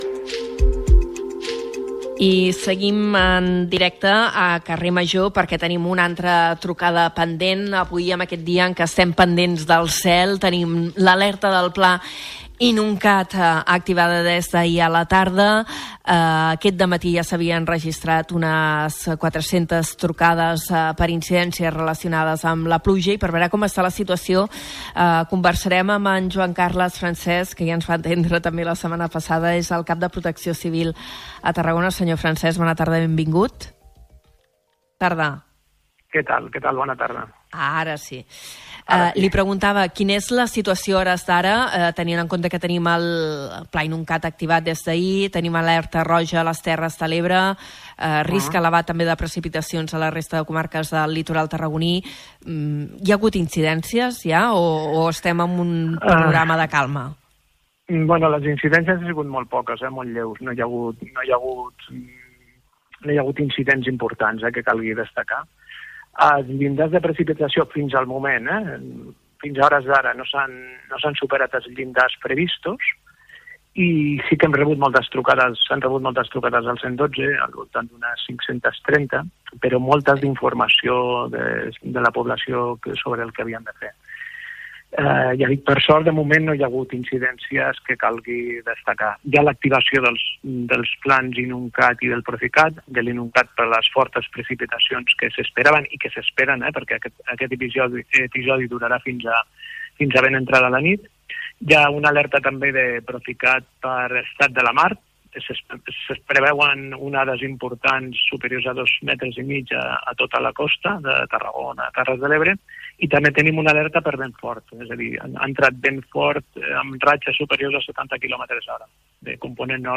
Entrevista a la vista | Ràdio Ciutat de Tarragona Podcast